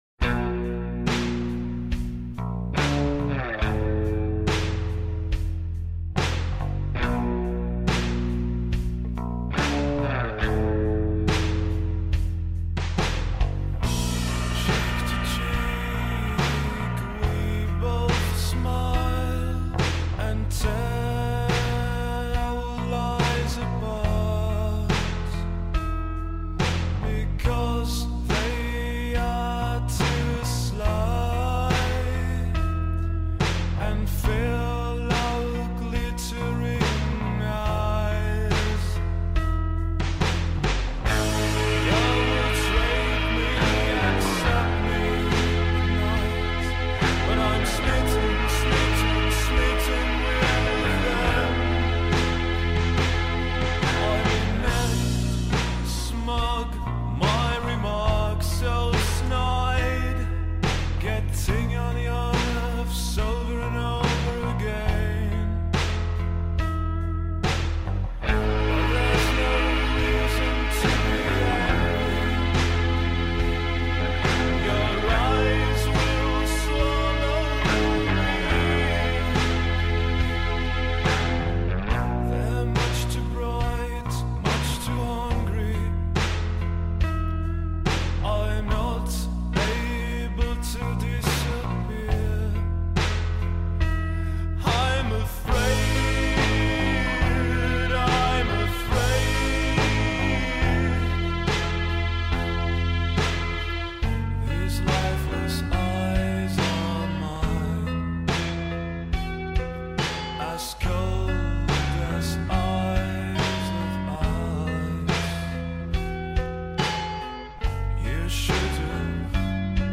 Žánr: Indie/Alternativa
Nahráno během roku 2006 v Praze a Říčanech.